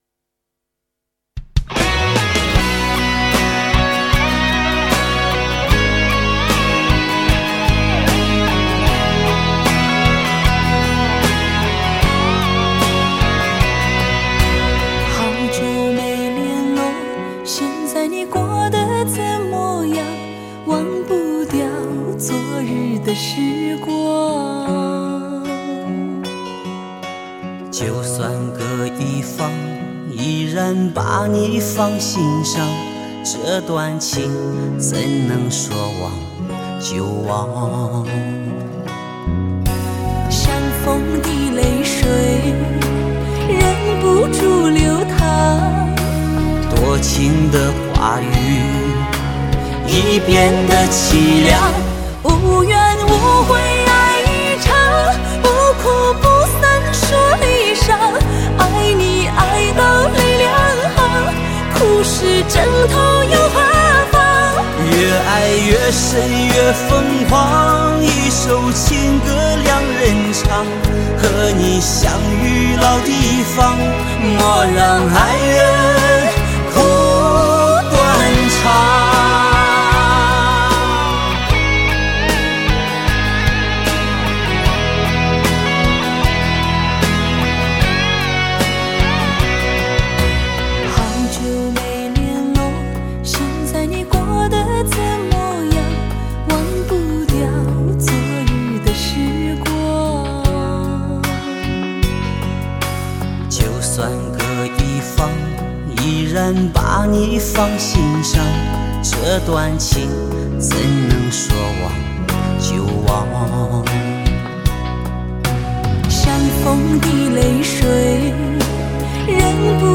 磁性的嗓音，细腻的演绎